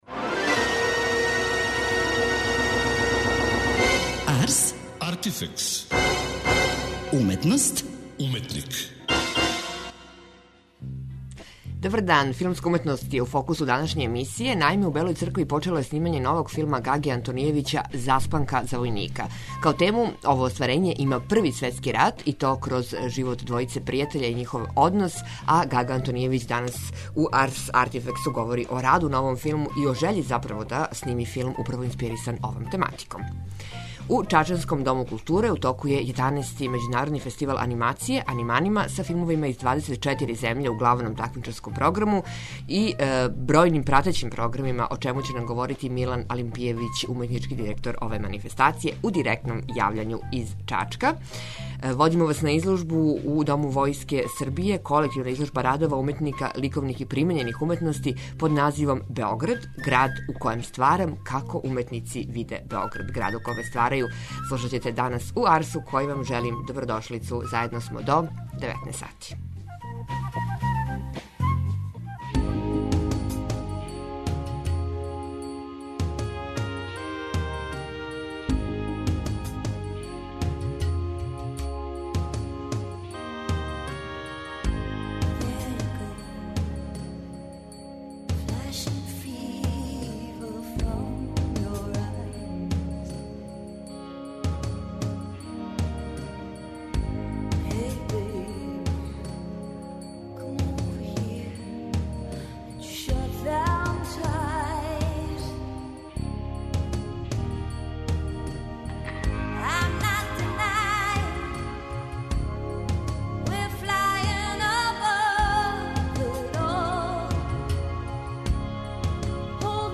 Морал, етика, пожртвованост - теме су које су инспирисале Гагу Антонијевића да крене у овај пројекат, о чему говори у данашњој емисији.